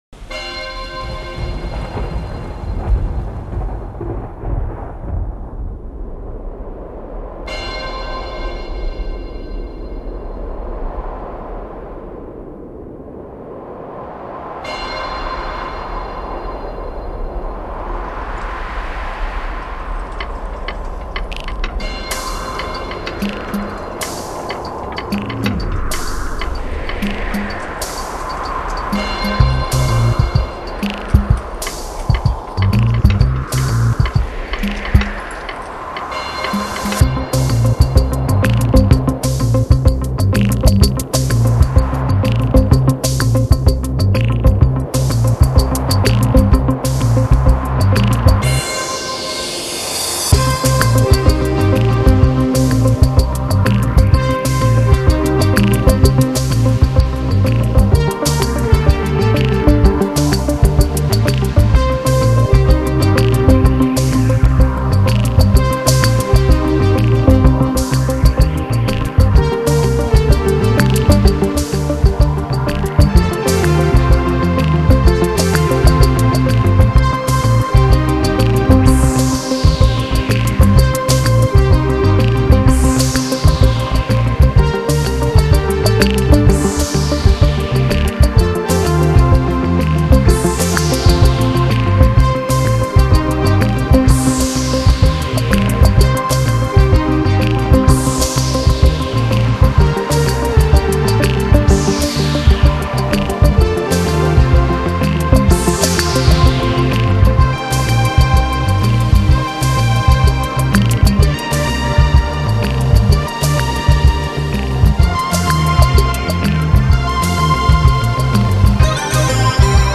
这张世界上第一张完全地用5.1环绕声来表现每一个音符的专辑。